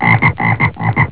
frog.au